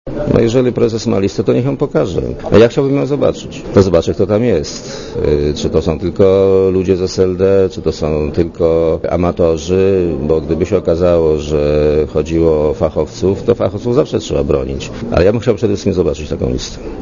Dla Radia Zet mówi premier Leszek Miller (66 KB)